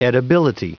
Prononciation du mot edibility en anglais (fichier audio)
Prononciation du mot : edibility